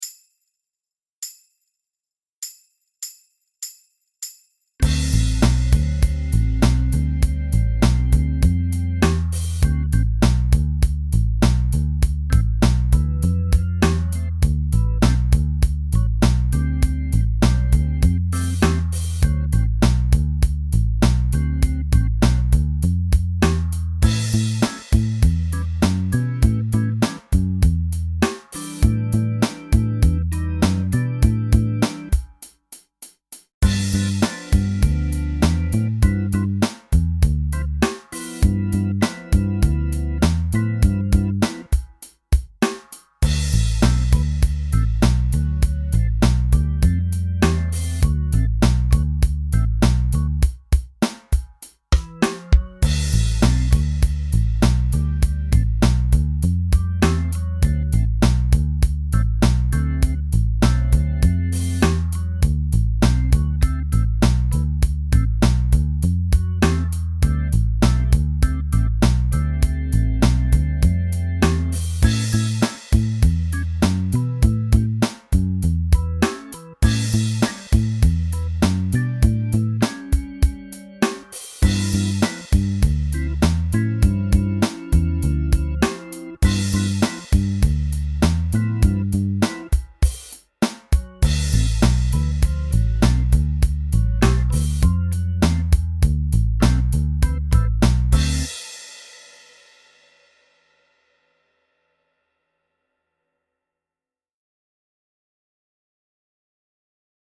notated bass lines in the classic styles
11 - Motown.mp3